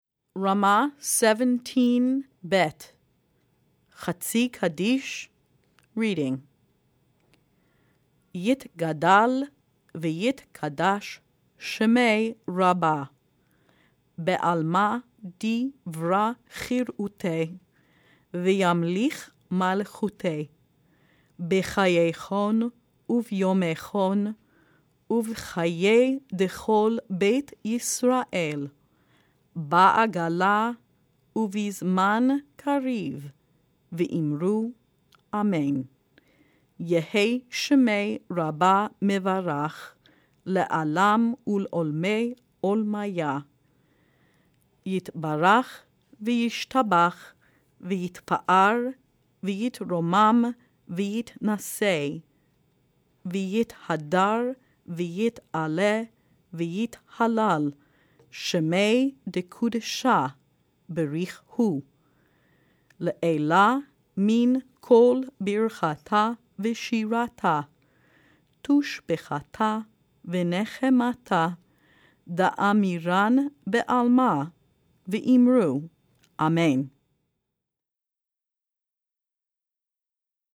R17 Chatzi Kaddish Read.mp3